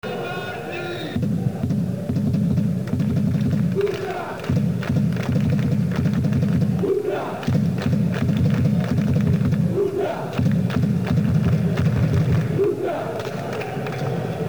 I cori in Mp3 degli Ultrà Lodigiani
Purtroppo l'acustica non è perfetta ma questo è quanto riusciamo a fare.
Partite varie in casa